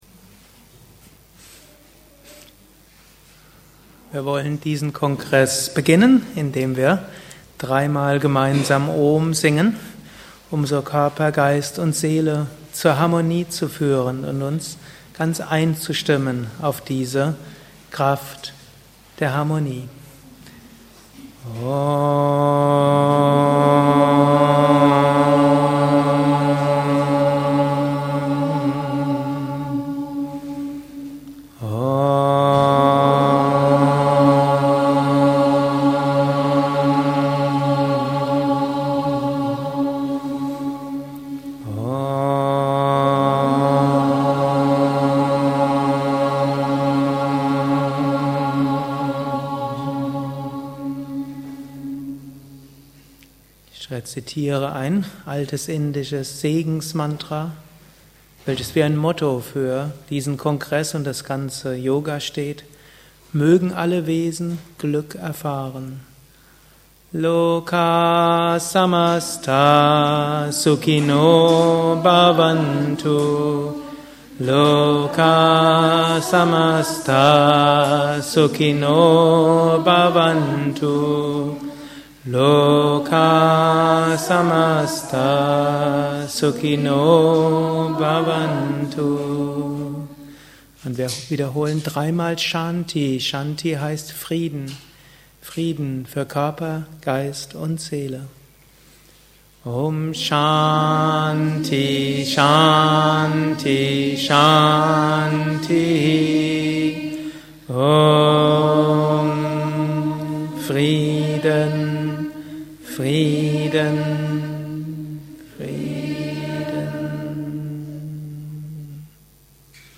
Eröffnungsreden des Kinderyoga Kongresses im Juni 2008 im Haus Yoga Vidya Bad Meinberg.